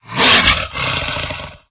pdog_attack_2.ogg